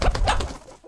CosmicRageSounds / wav / general / combat / creatures / CHİCKEN / he / attack2.wav